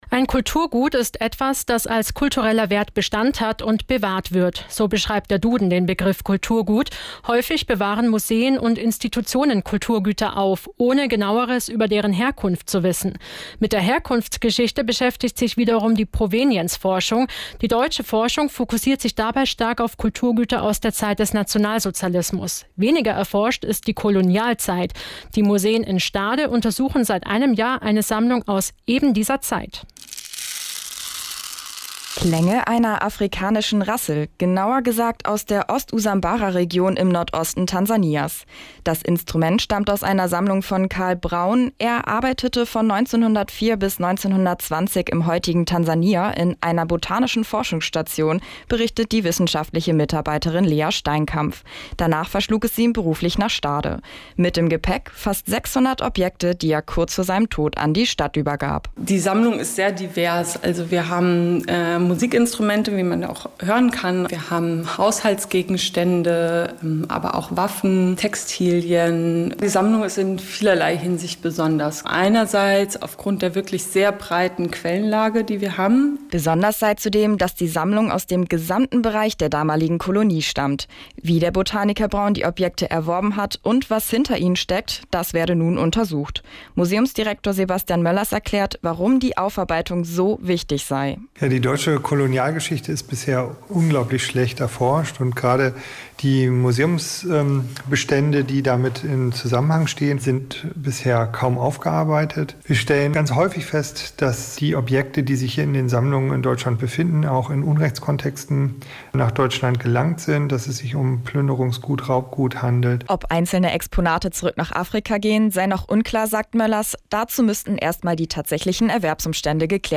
Mitschnitt_NDR1_Kolonialforschung.mp3